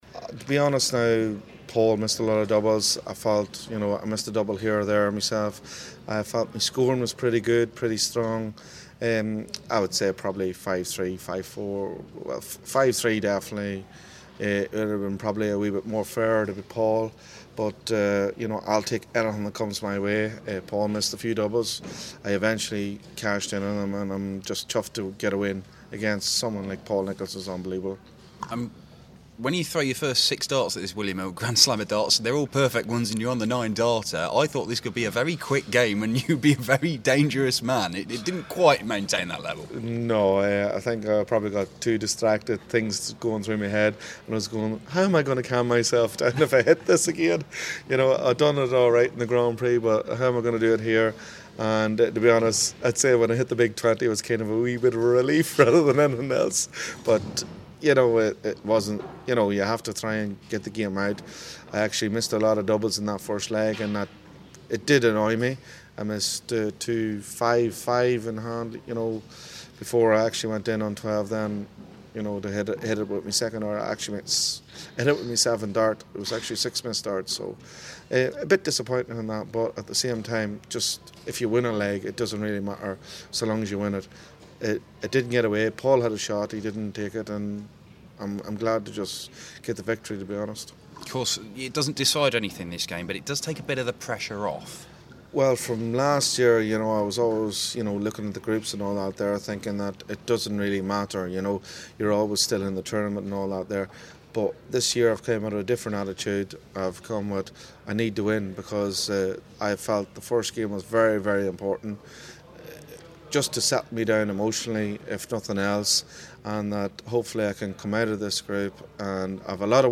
William Hill GSOD - Dolan Interview
Brendan Dolan and his pseudo-Taylor 'tache, after beating Paul Nicholson 5-2 at the William Hill Grand Slam of Darts.